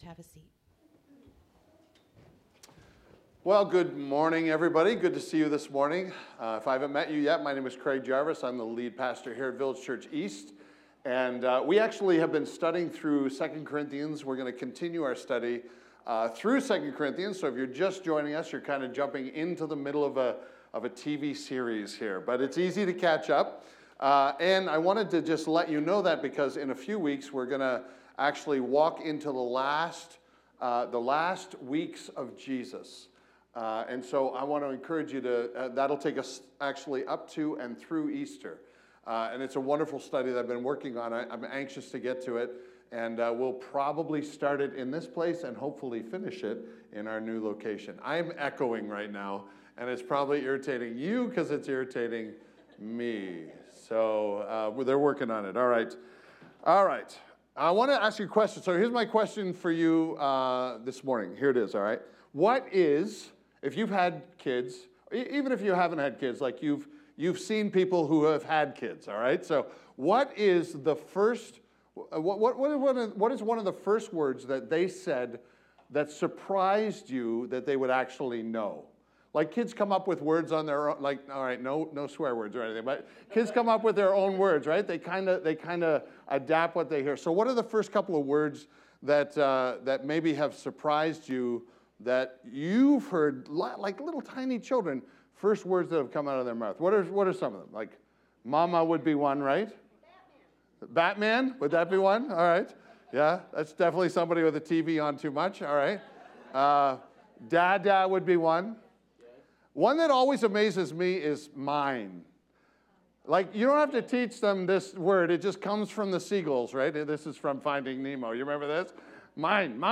Village Church East: Sermons